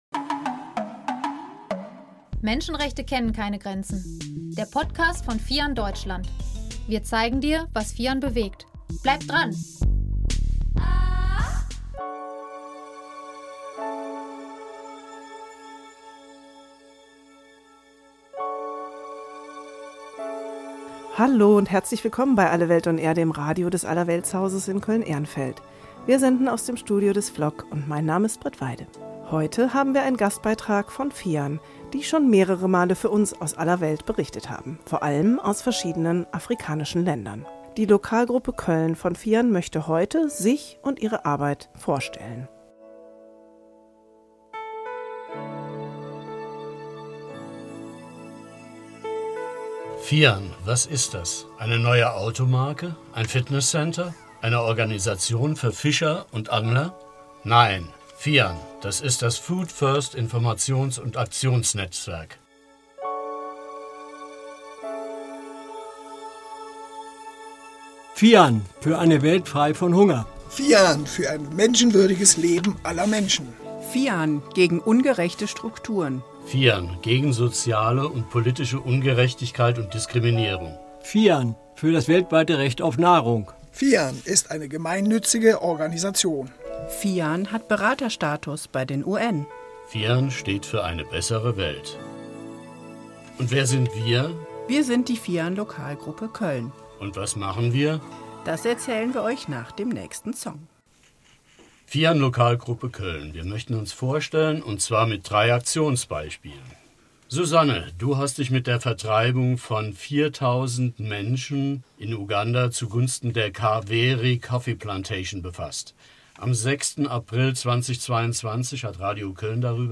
Radiobeitrag der Lokalgruppe Köln: Arbeit und Erfolge von FIAN - FIAN Deutschland e.V.